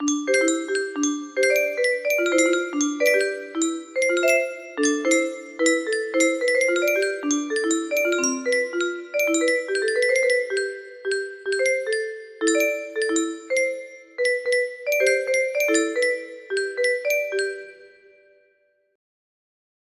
Mini Happy Zooms music box melody